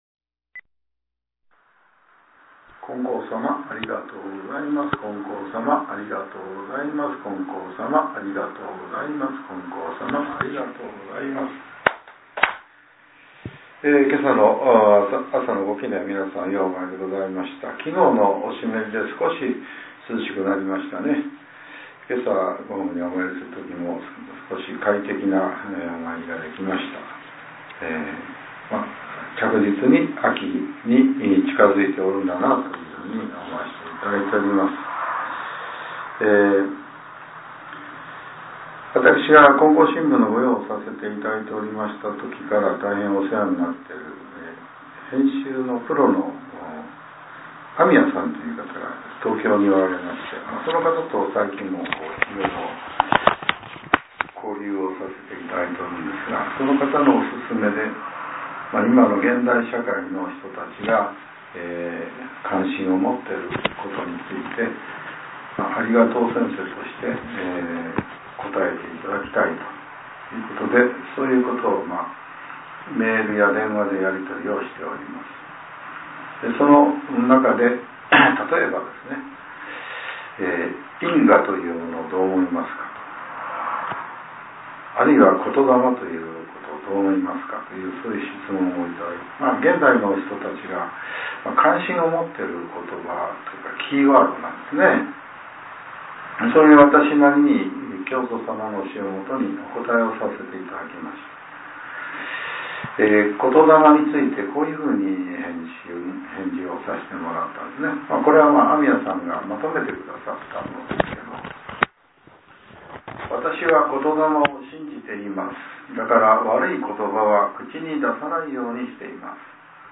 令和７年８月２８日（朝）のお話が、音声ブログとして更新させれています。